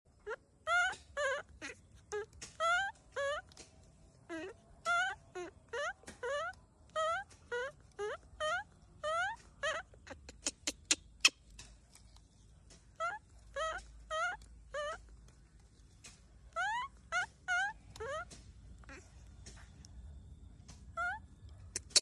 VALIS sings so cutely 🥰🥰🥰😍😍🇬🇧🇬🇧🇬🇧🇬🇧 sound effects free download